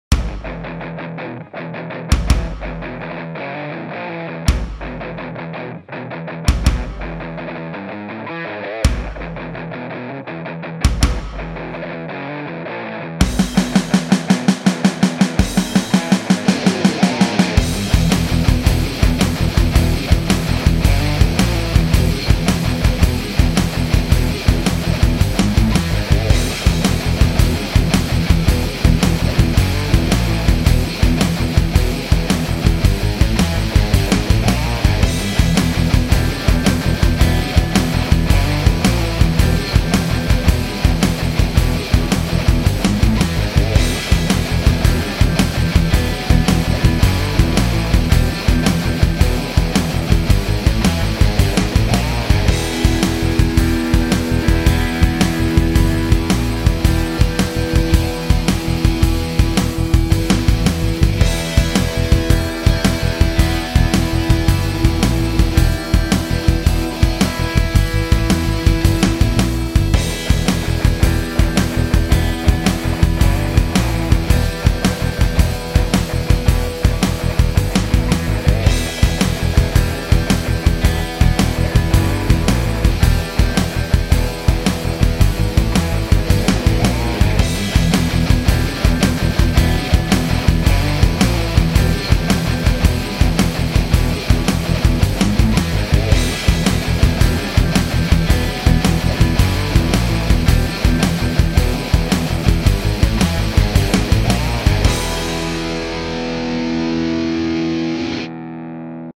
Category: Education